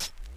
Closed Hat (True Colors).wav